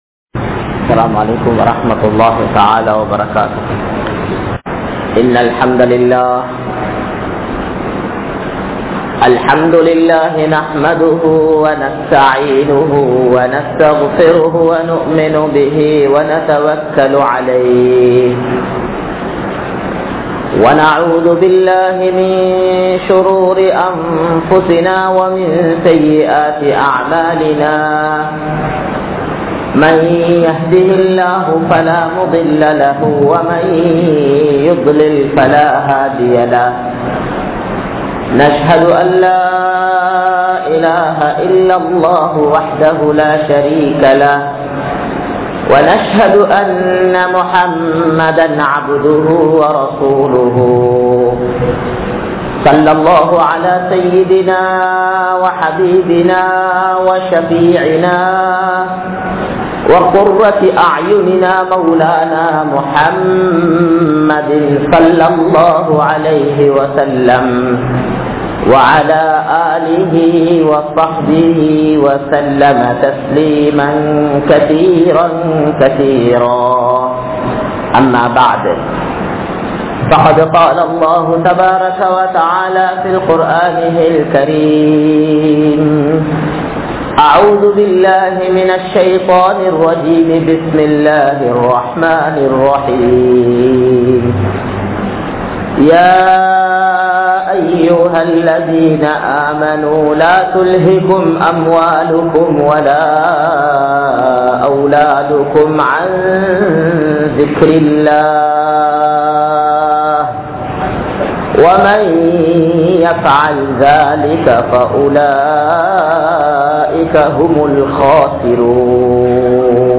Dheenilthaan Veattri Ullathu (தீணில்தான் வெற்றி உள்ளது) | Audio Bayans | All Ceylon Muslim Youth Community | Addalaichenai